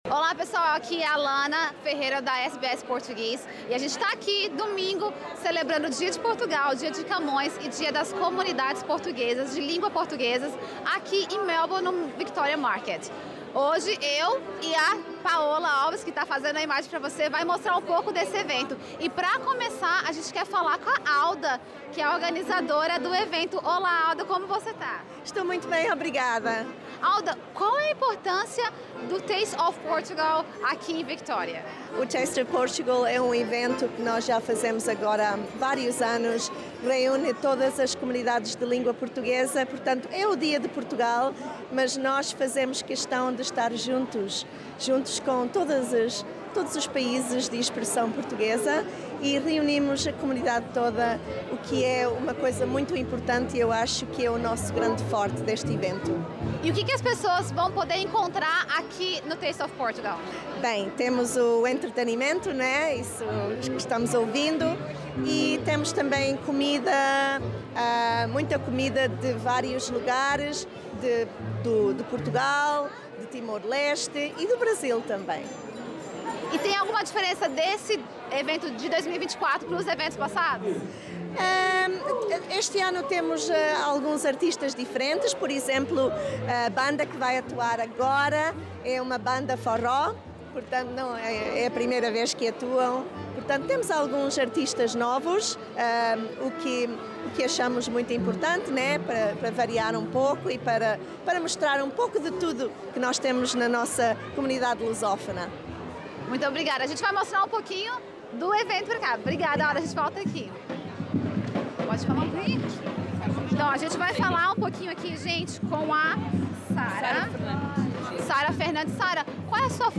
O Dia de Portugal, de Camões e das Comunidades Portuguesas é celebrado anualmente a 10 de junho. Em Victoria, o evento que celebra a cultura portuguesa e das comunidades de fala portuguesa, Taste of Portugal Festival, aconteceu no conhecido Victoria Market, e a SBS Portuguese acompanhou a festa.